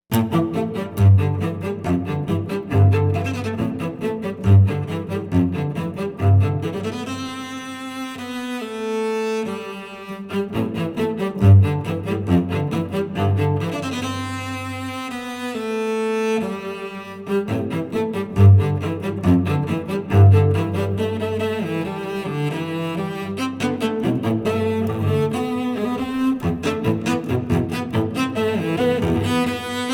Жанр: Поп музыка / Соундтрэки